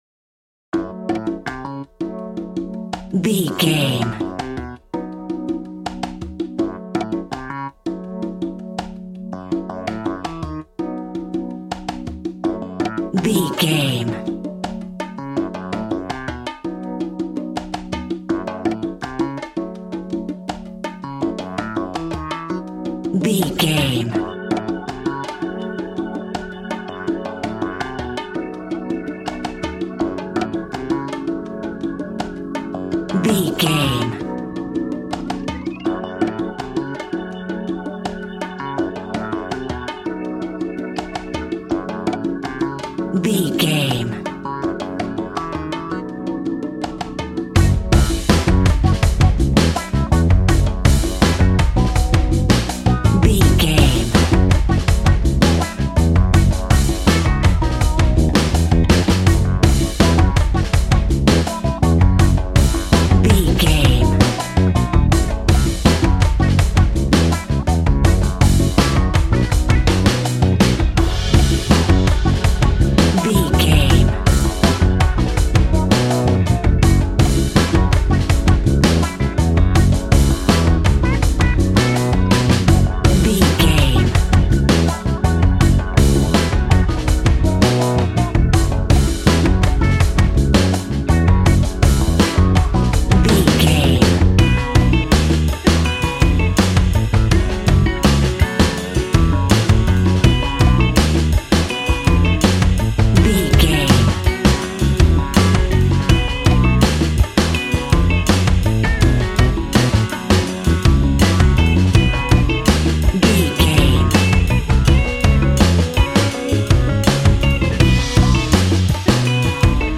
Aeolian/Minor
G♭
relaxed
smooth
synthesiser
drums
80s